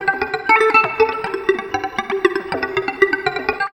78 GTR 6  -R.wav